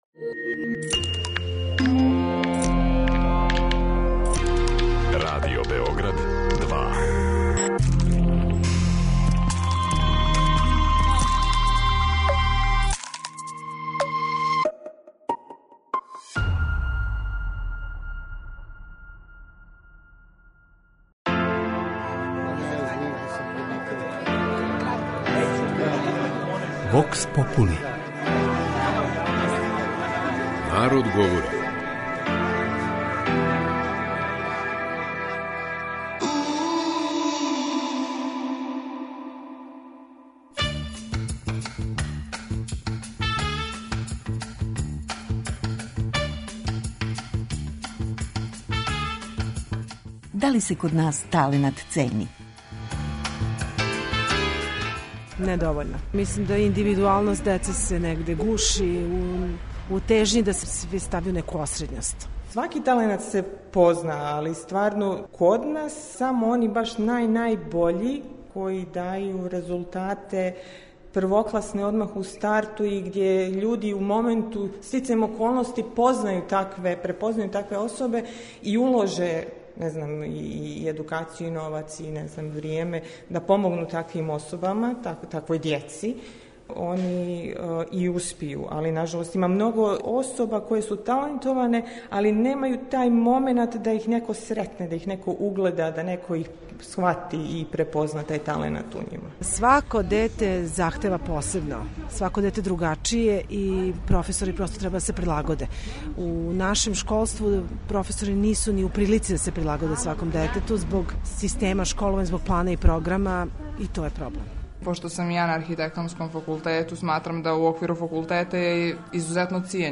кроз кратке монологе, анкете и говорне сегменте